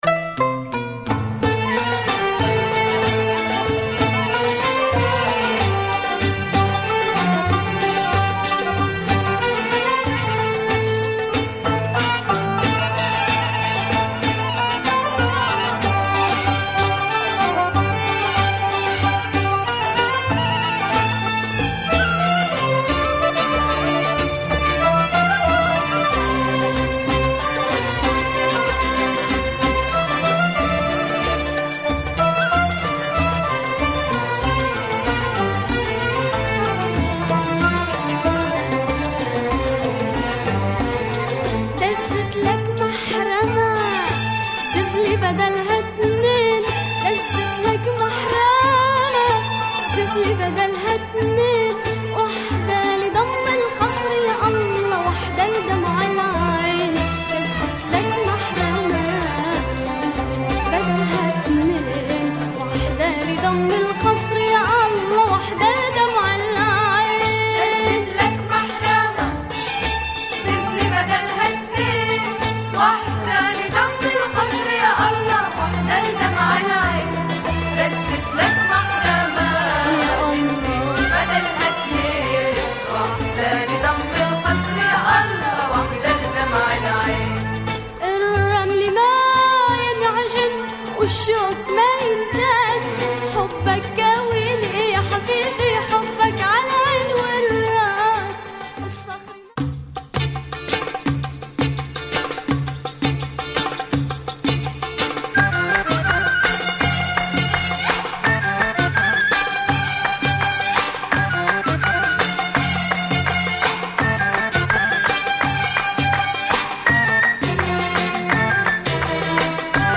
Super killer oriental beats !